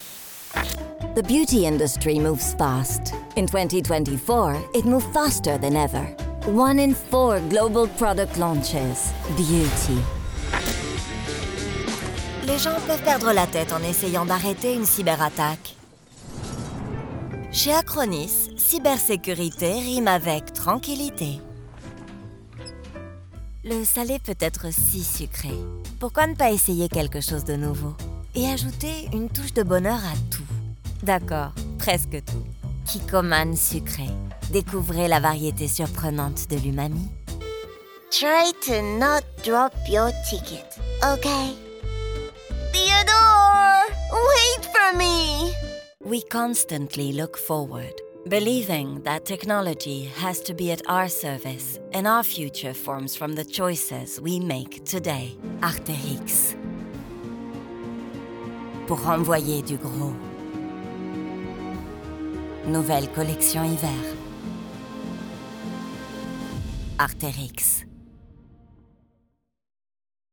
Demo REEL French English Voiceover
Voix - Contralto Mezzo-soprano Soprano